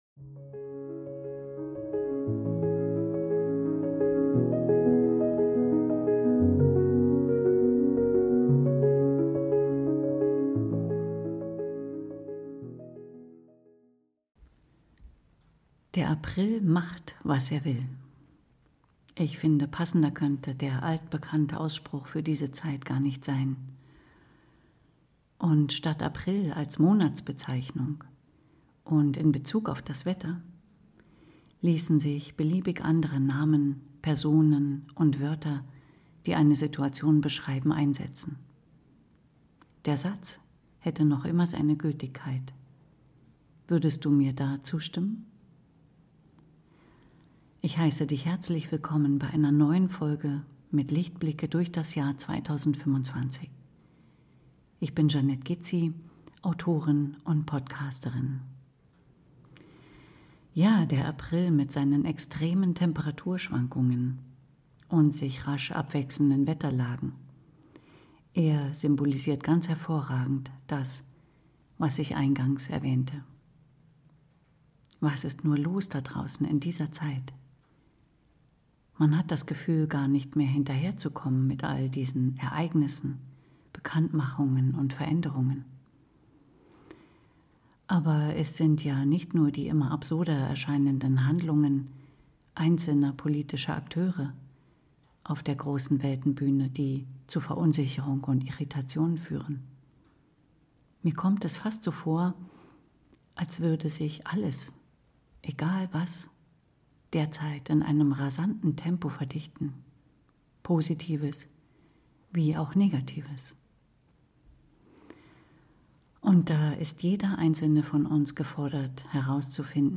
mit anschließender Klangschalenreise 432 Hz